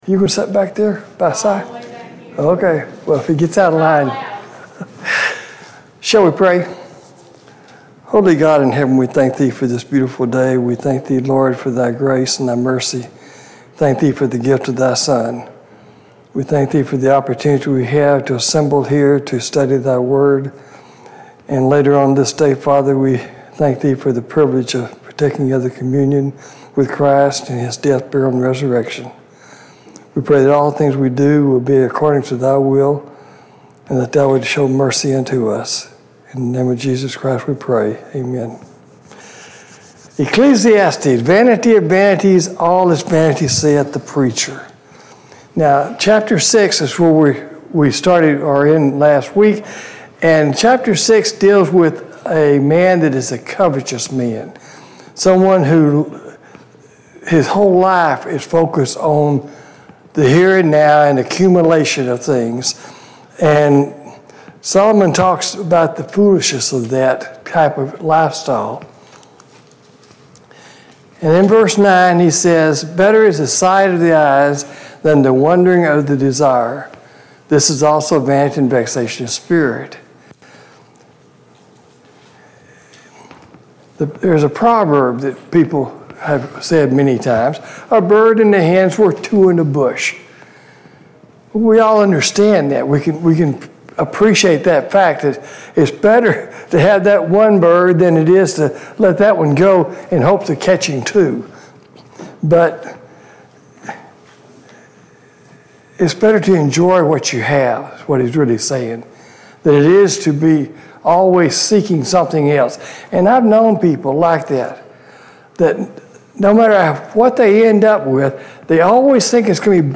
A Study of Ecclesiastes Service Type: Sunday Morning Bible Class « Lessons learned from the life of King Solomon Walking my Walk with Christ